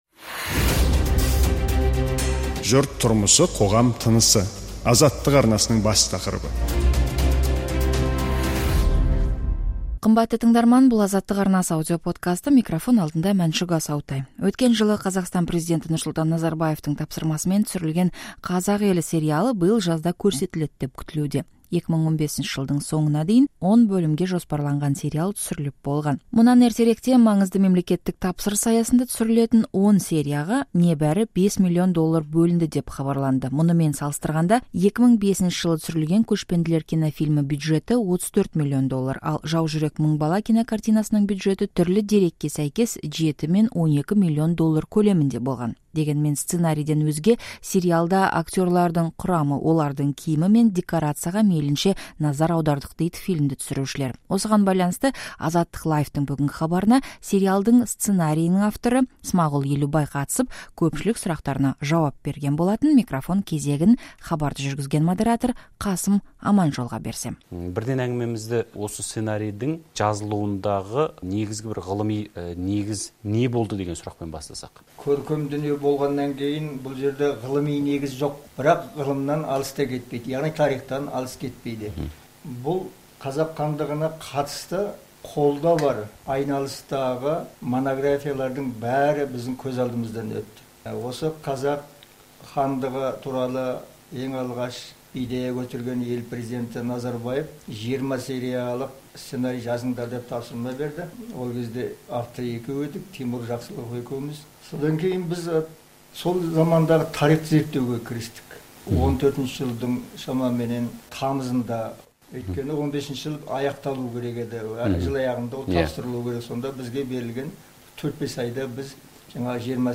Көпшілік күтіп жүрген "Қазақ елі" сериалы жайында сценарий авторы Смағұл Елубай AzattyqLIVE-та сұхбат берді.